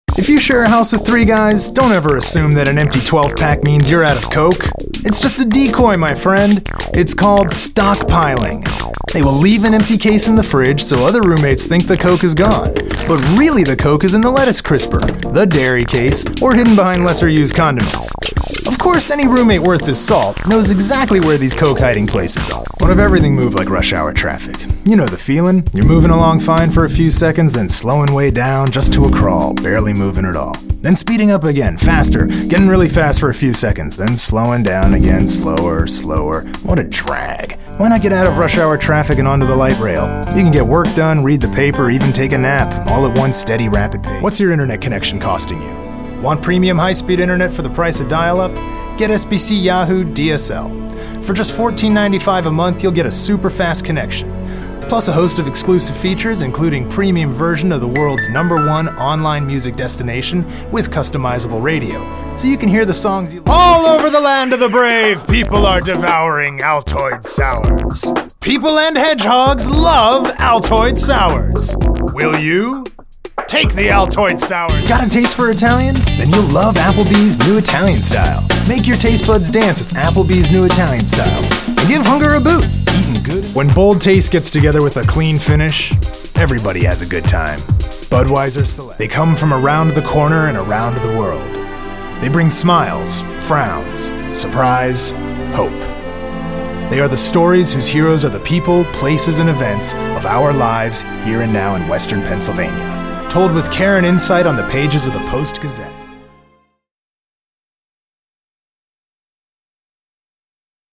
Demo voz 2010_
Acento Norteamericano // Neutral Nativo Florida (EE.UU)